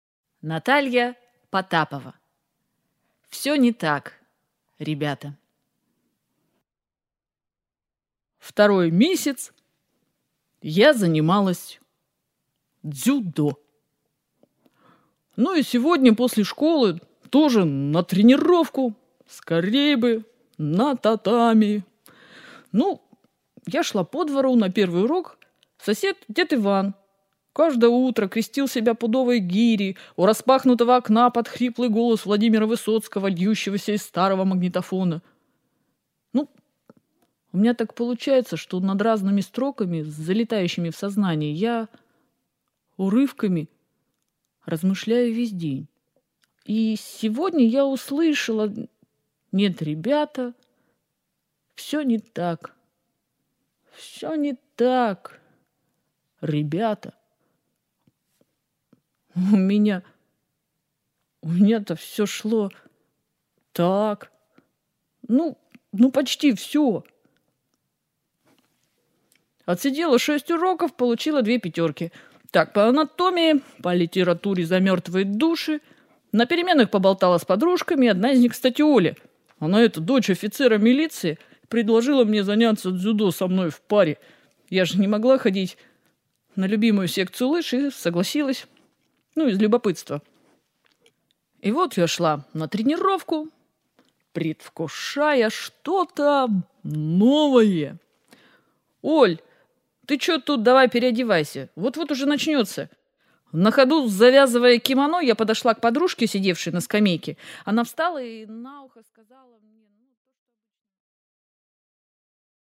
Аудиокнига Лекарство от боли | Библиотека аудиокниг